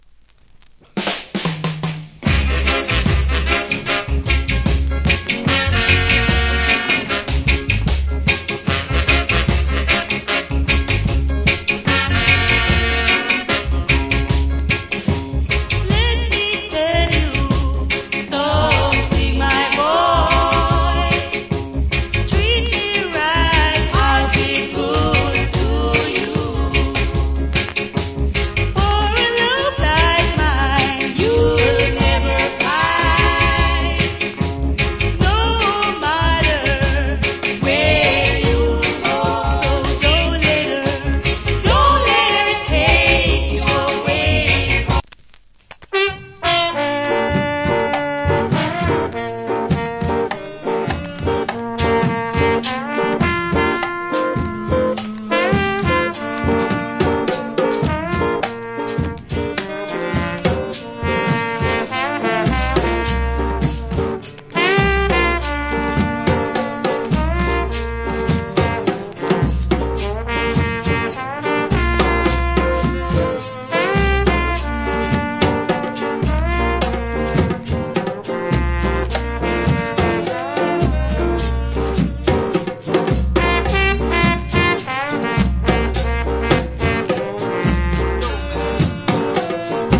Big Shot Early Reggae Vocal.